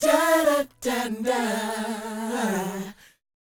DOWOP E 4D.wav